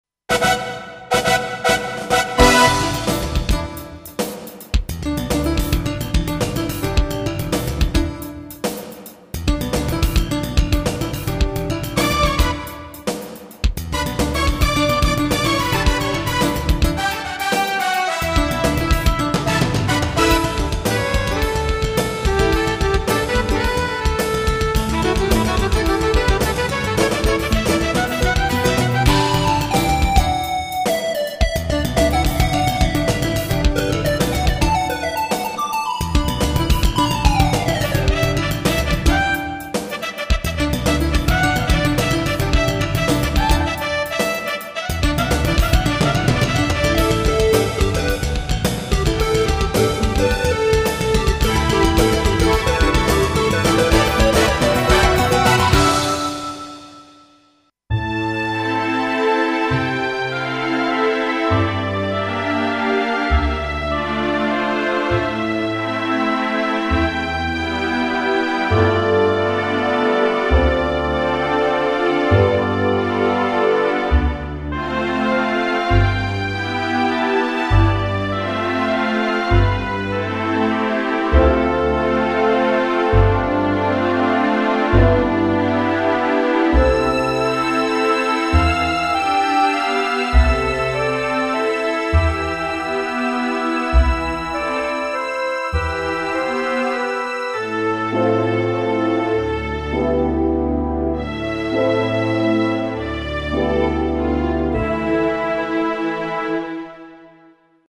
Roland SC-8850 ED Sound Module